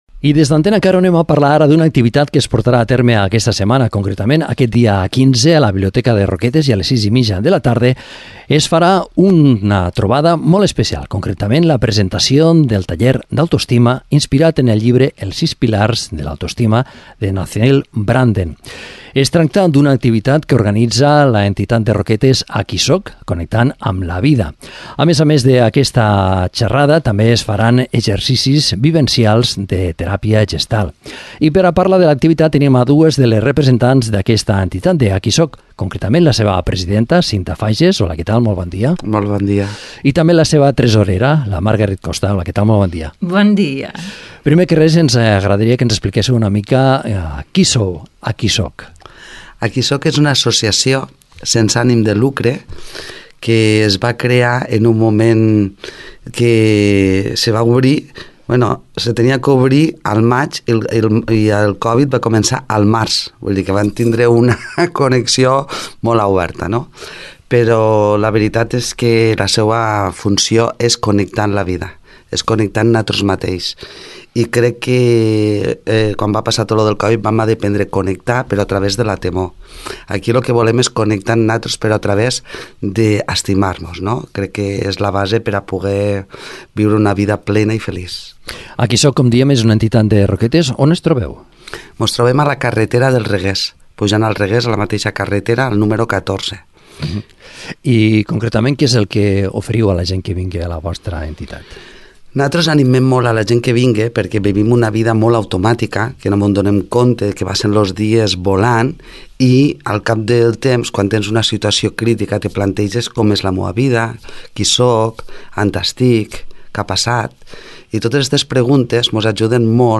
Entrevistem a dues membres